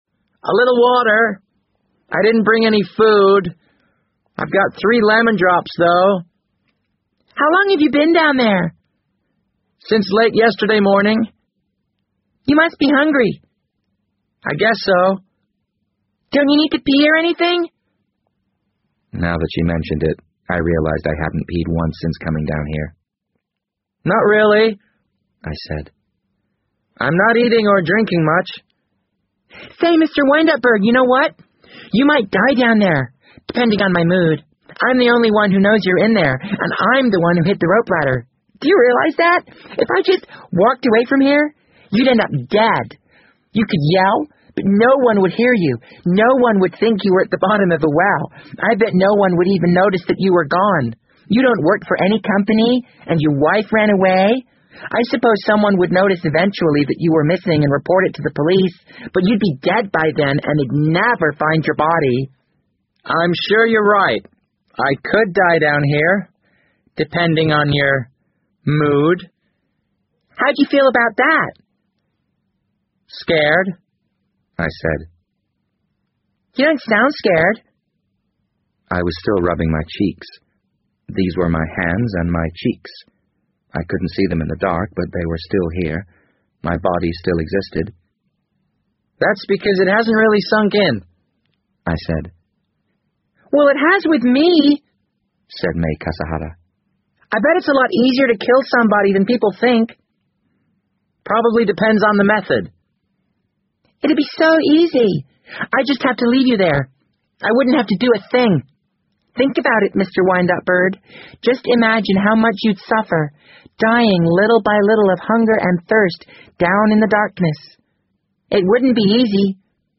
BBC英文广播剧在线听 The Wind Up Bird 007 - 5 听力文件下载—在线英语听力室